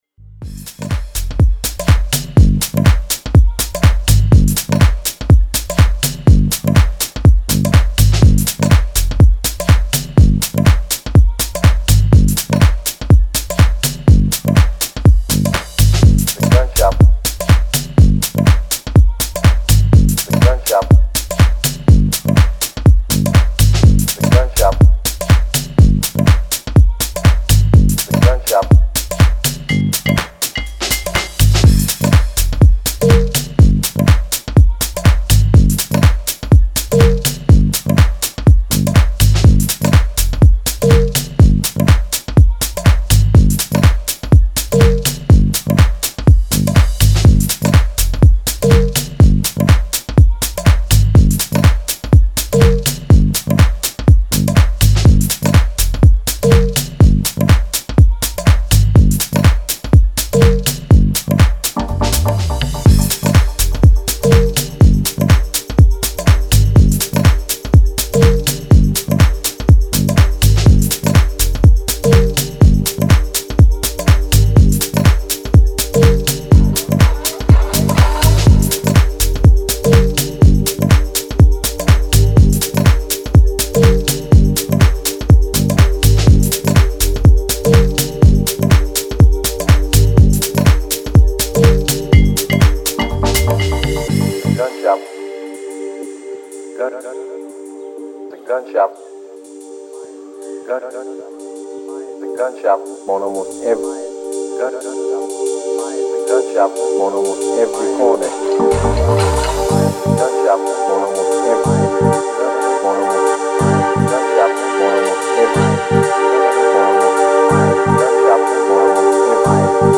Style: Deep House / Tech House